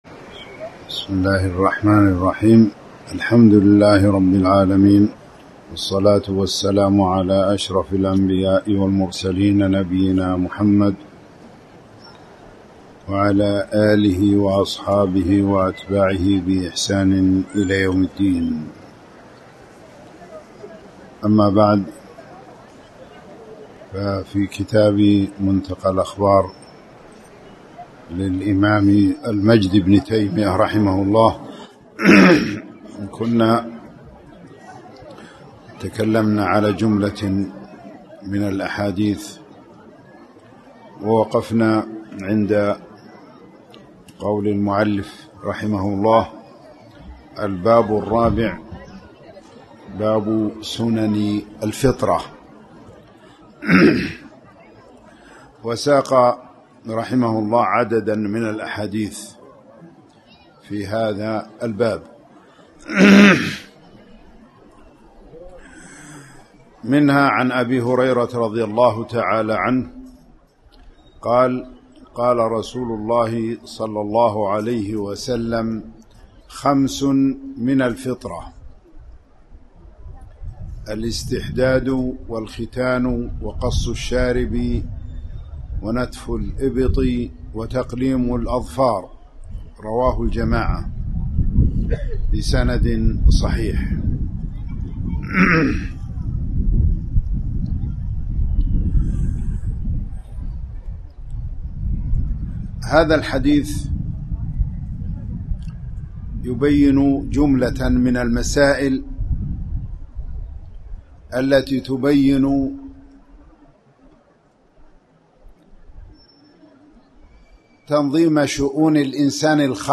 تاريخ النشر ٢٧ محرم ١٤٣٩ هـ المكان: المسجد الحرام الشيخ